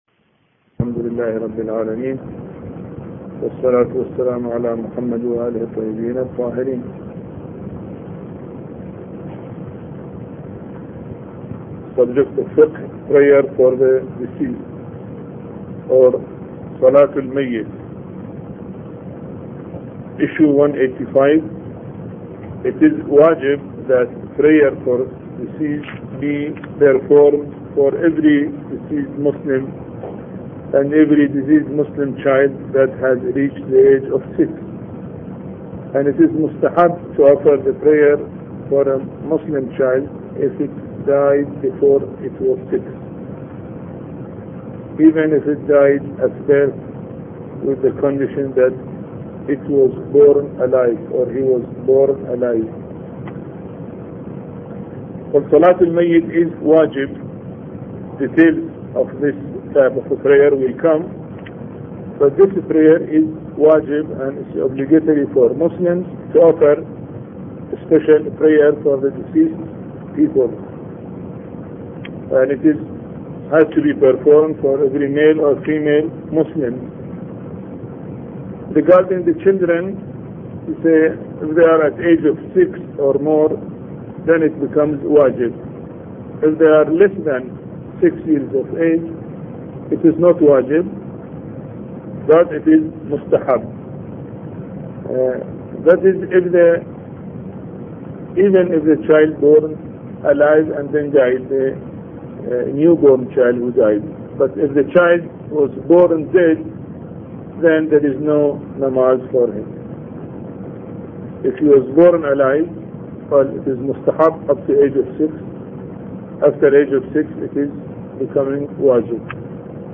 A Course on Fiqh Lecture 10